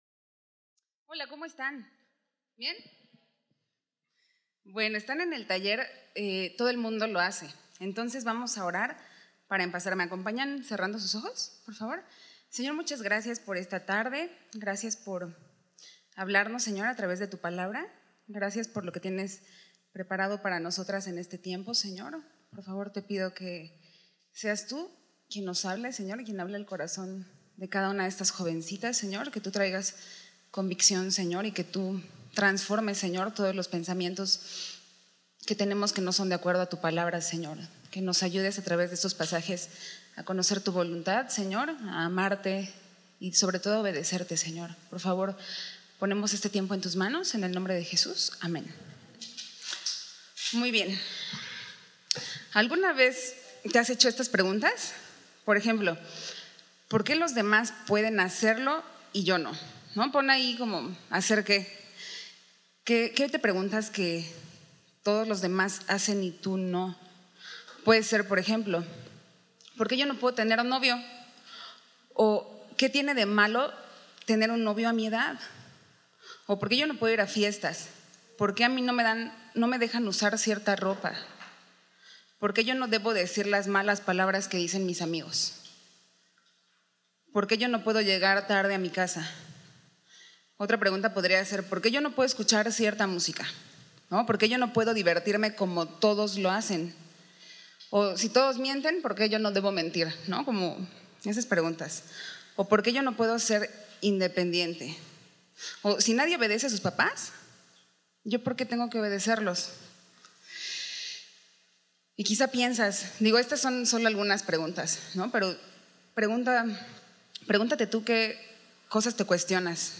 Taller – Todo el mundo lo hace
Retiro de Jovencitas